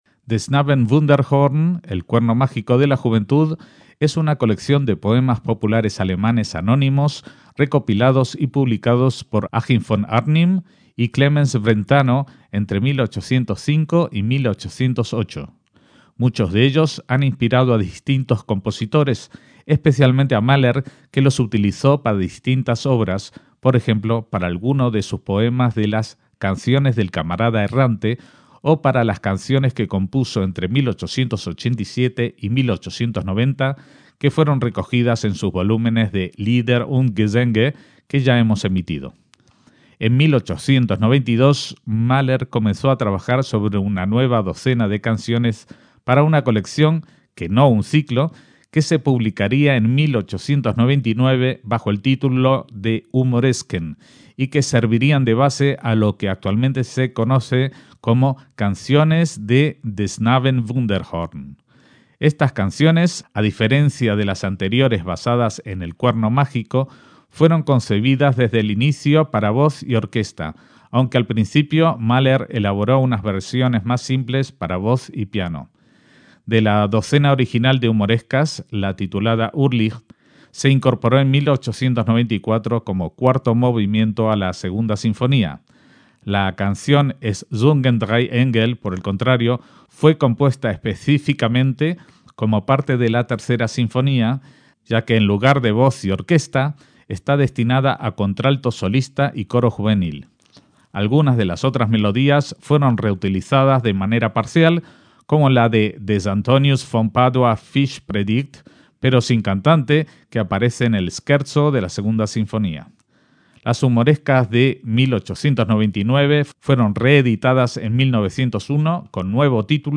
lieder (canciones artísticas)
acompañamiento orquestal